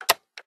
click.wav